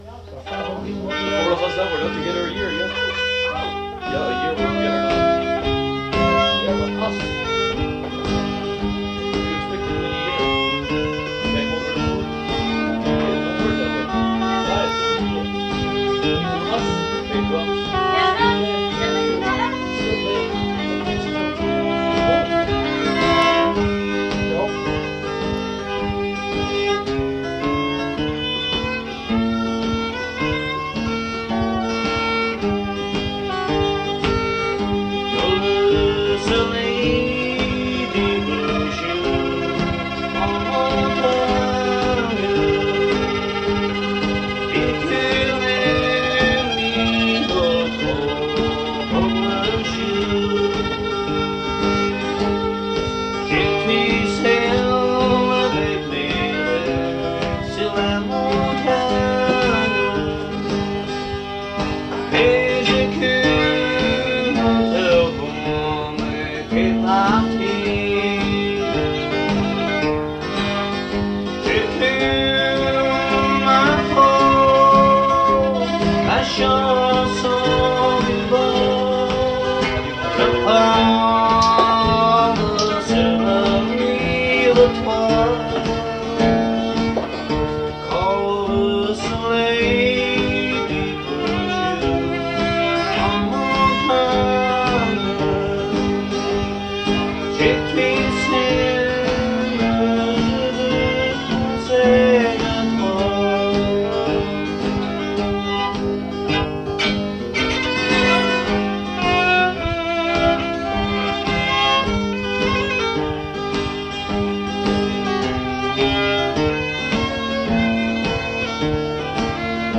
Avec guitare et violon